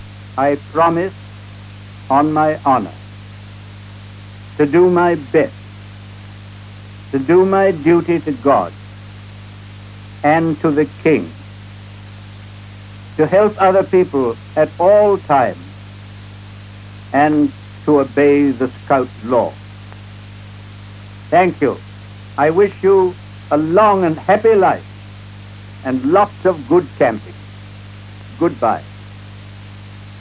B.-P.  pronuncia la promessa nel 1937 in occasione del suo 80° compleanno